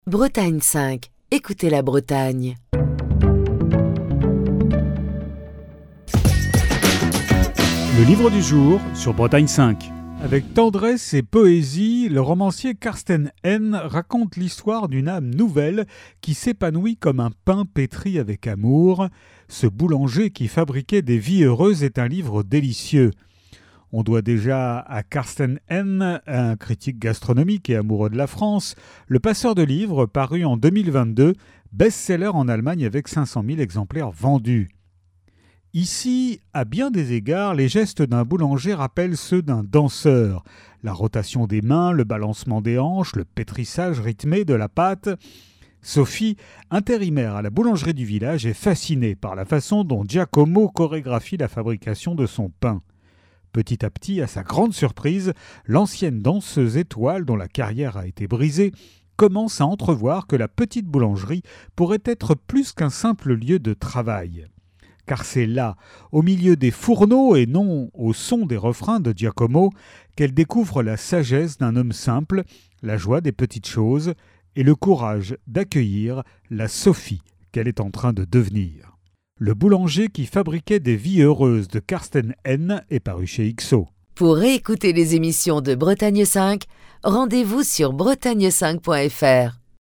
Chronique du 9 octobre 2024.